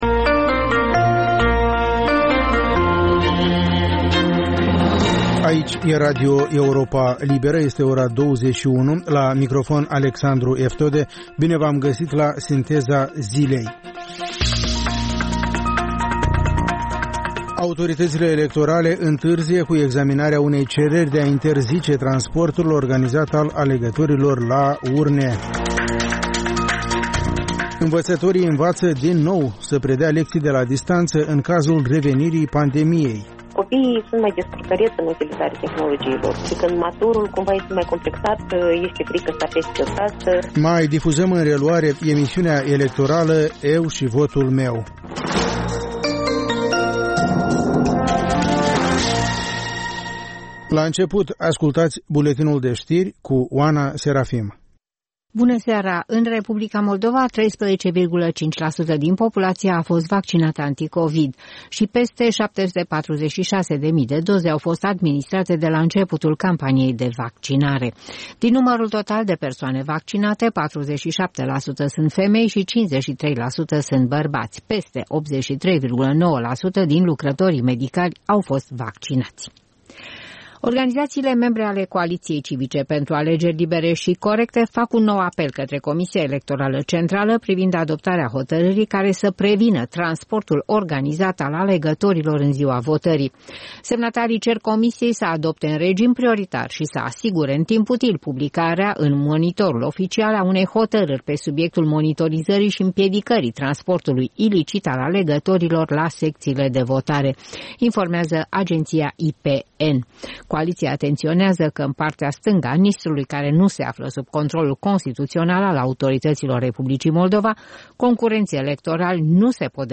Ştiri, interviuri, analize şi comentarii.